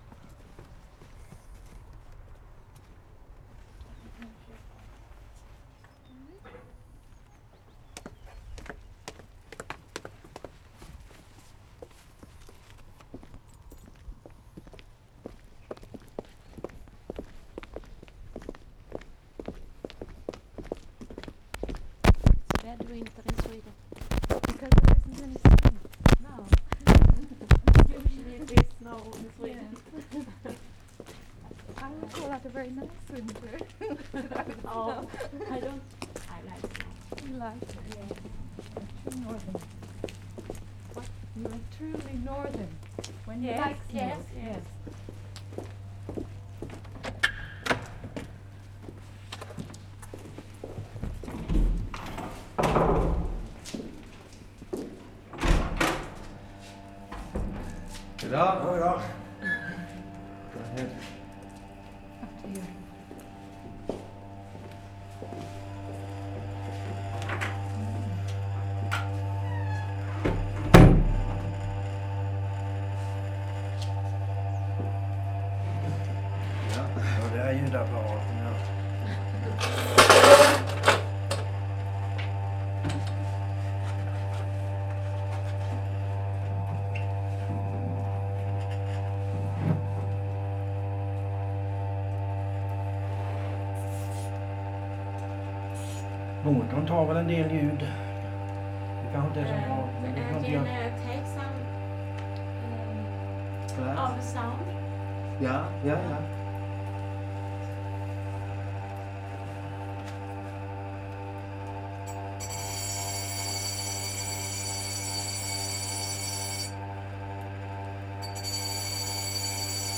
WORLD SOUNDSCAPE PROJECT TAPE LIBRARY
GLASS ENGRAVING
8. Walking into basement, some distant talking & laughing, doors opening, change in ambience sound of the grinding machine, with and without grinding, not unpleasant continuous sound, not very loud.